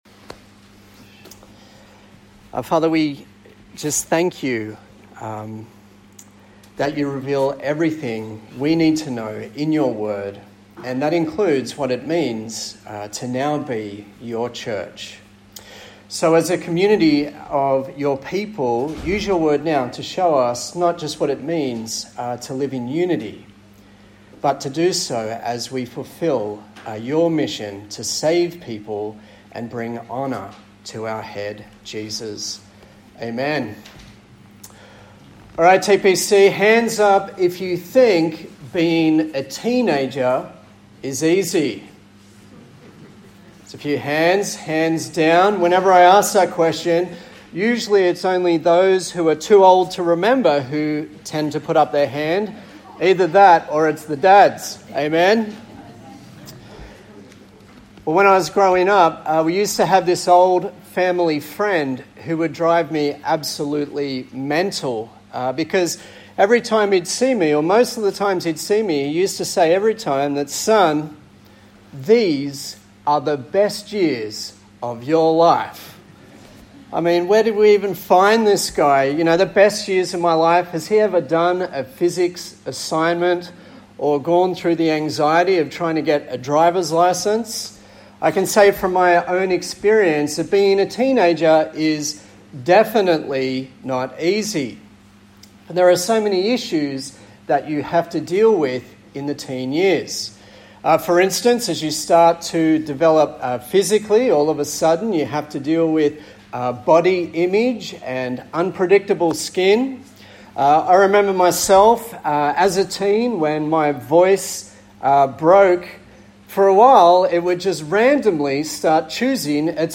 Acts Passage: Acts 6:1-7 Service Type: Sunday Morning